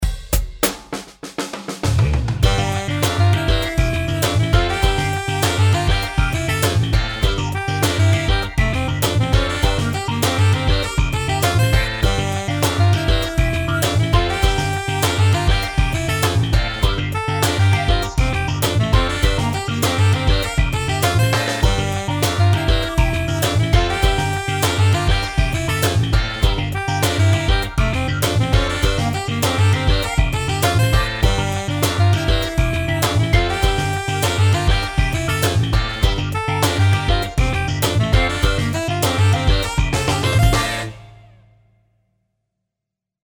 音楽ジャンル： ロック
楽曲の曲調： MIDIUM